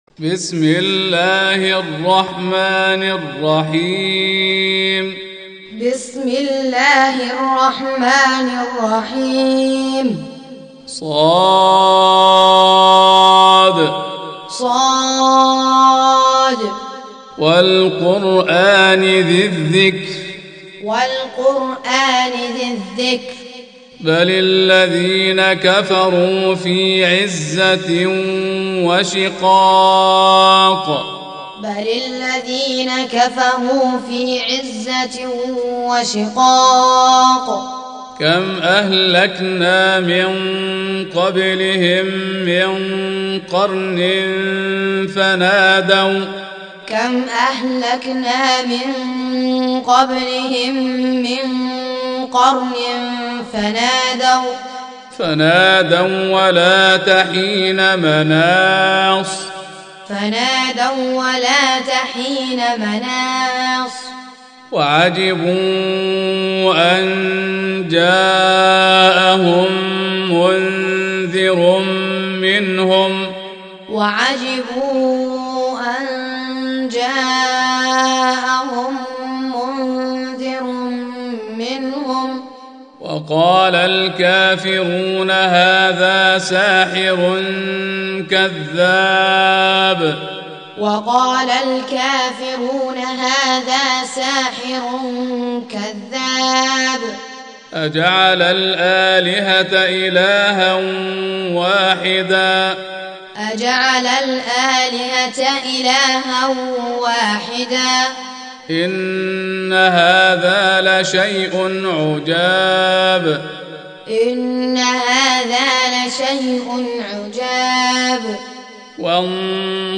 Audio Quran Taaleem Tutorial Recitation Teaching Qur'an One to One
Surah Sequence تتابع السورة Download Surah حمّل السورة Reciting Muallamah Tutorial Audio for 38. Surah S�d. سورة ص N.B *Surah Includes Al-Basmalah Reciters Sequents تتابع التلاوات Reciters Repeats تكرار التلاوات